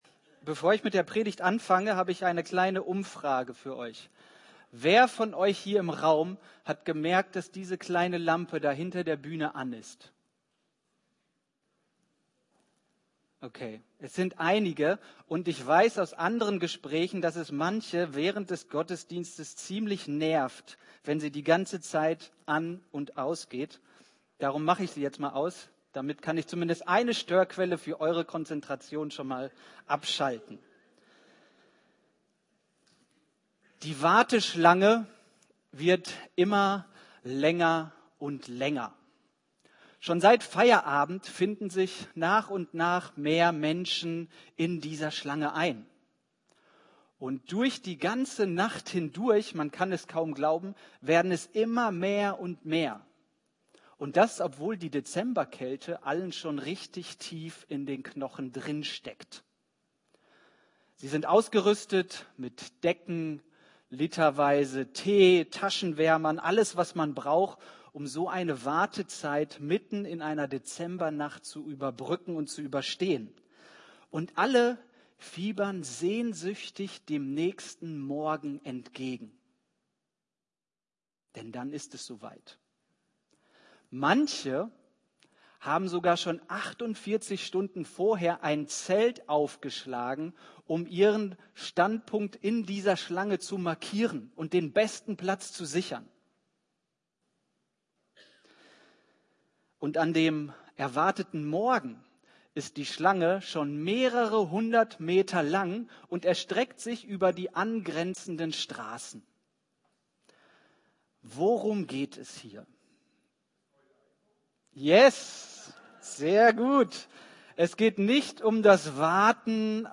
Predigt Evangelien